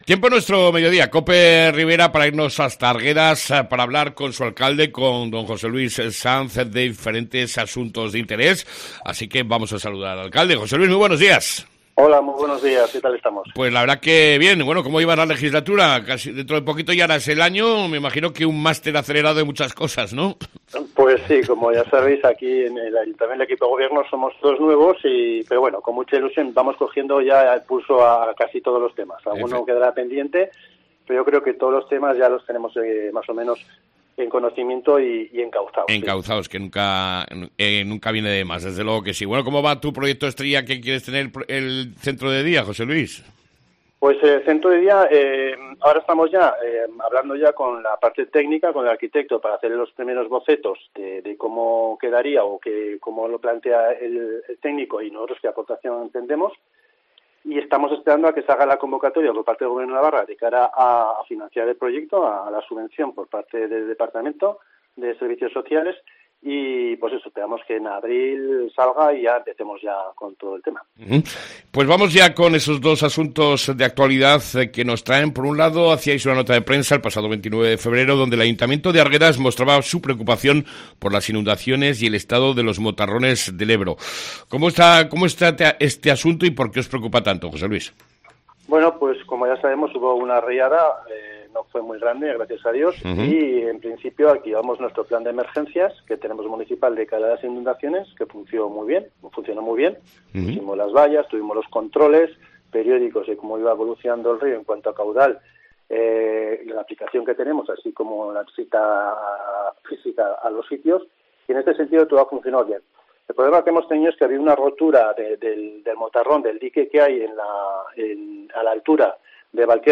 Entrevista con el Alcalde de Arguedas , José Luis Sanz